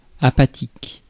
Prononciation
Synonymes fatigué malade mou impassible nonchalant indolent amorphe Prononciation France: IPA: [a.pa.tik] Le mot recherché trouvé avec ces langues de source: français Traduction 1.